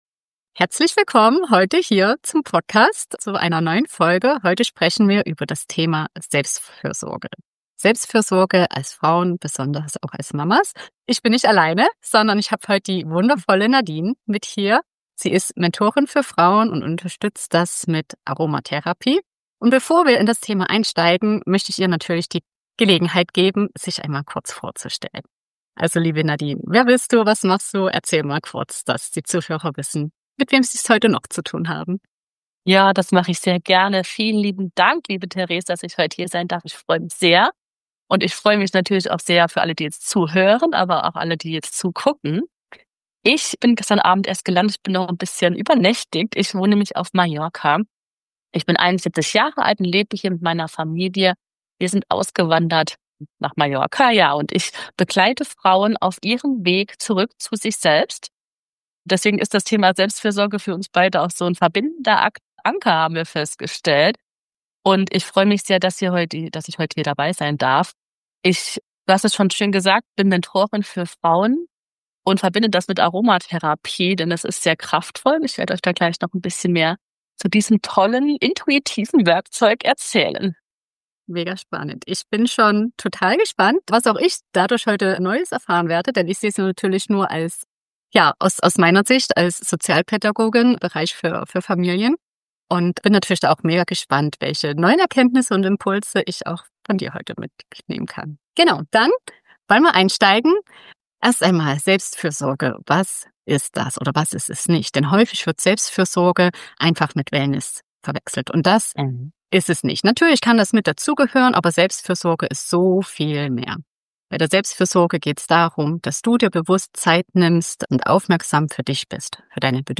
Ein ehrliches, inspirierendes Gespräch mit vielen wertvollen Impulsen.